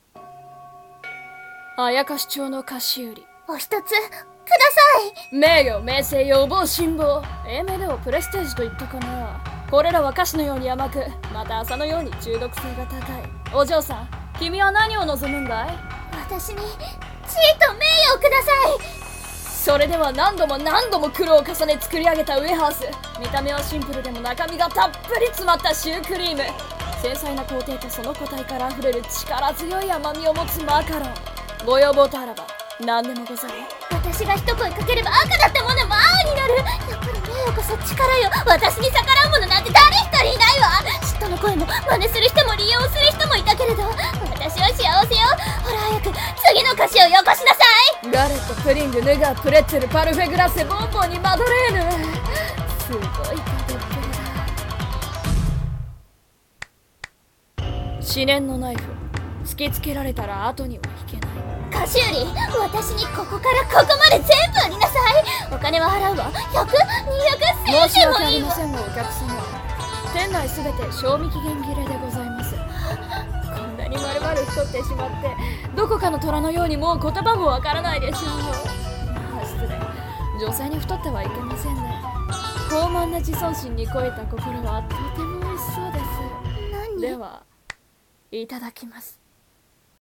CM風声劇「妖町の菓子売